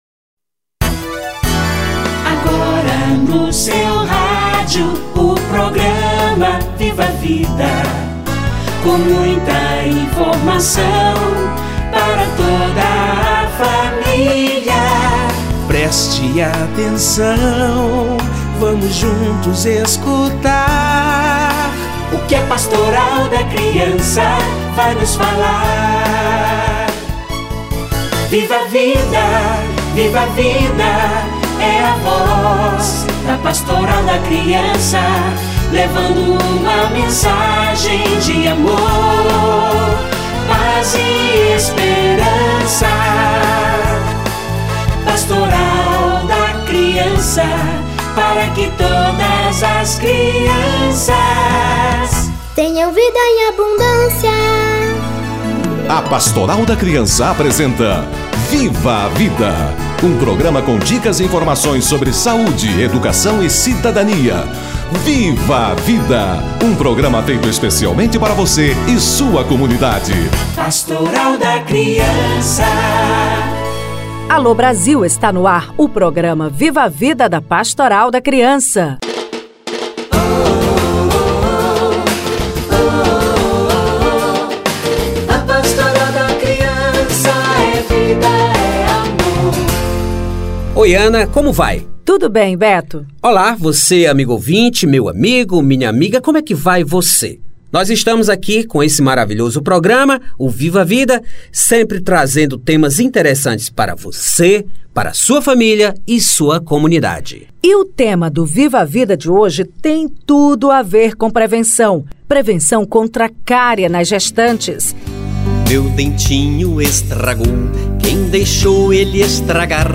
Saúde bucal da gestante - Entrevista